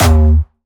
Jumpstyle Kick 4
11 D#2.wav